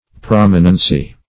prominency - definition of prominency - synonyms, pronunciation, spelling from Free Dictionary
Prominence \Prom"i*nence\, Prominency \Prom"i*nen*cy\, n. [L.